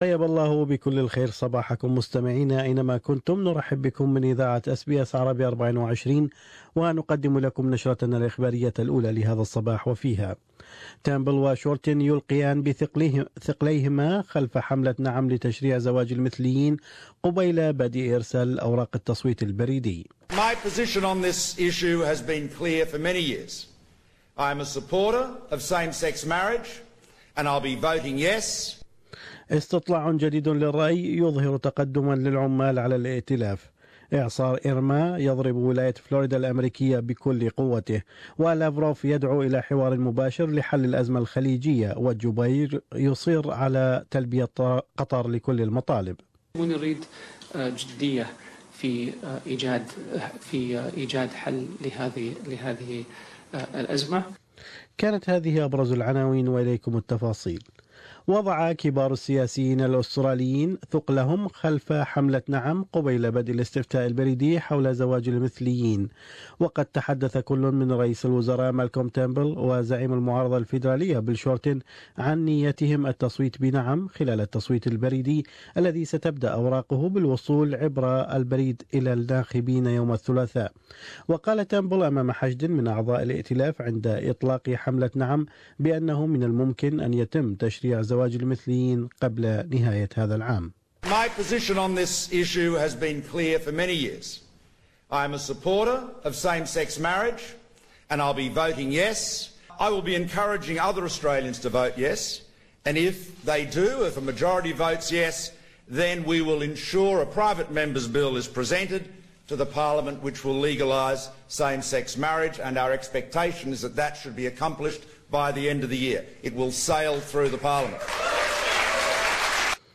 News Bulletin: Australia's political leaders throw their weight behind 'yes' campaign